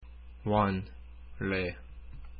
Pronunciación
Cuando la consonante final de una sílaba es ㄴ, y la primera consonante de la siguiente es ㄹ, la ㄴ se pronuncia como ㄹ.
원 + 래 (por separado)